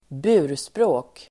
Ladda ner uttalet
burspråk substantiv, bay Uttal: [²b'u:r_språ:k] Böjningar: burspråket, burspråk, burspråken Definition: liten hängande utbyggnad på hus (med fönster) (small window projecting outwards from the wall of a building)